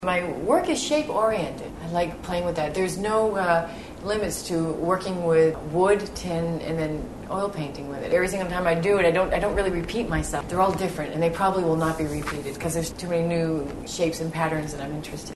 The Iowa Women Artists Oral History Project records and preserves the voices of women visual artists in Iowa reflecting on their lives and their artwork.